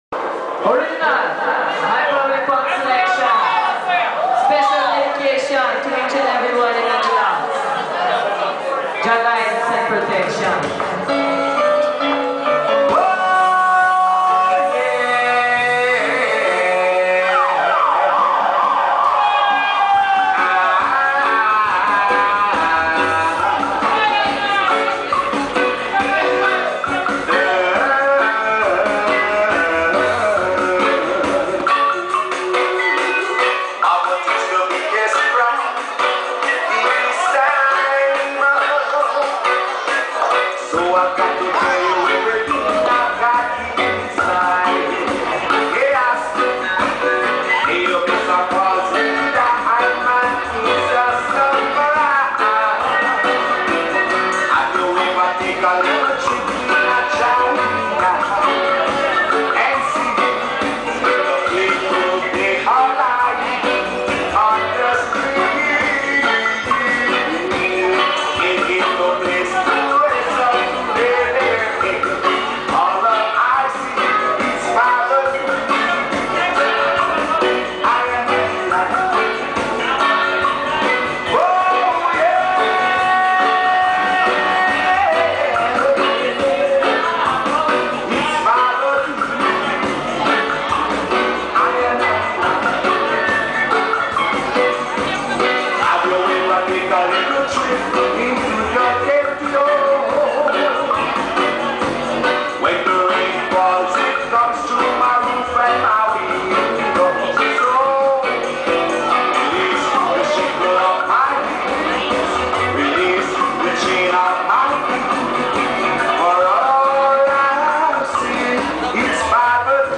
"Roots ad Culture" Style...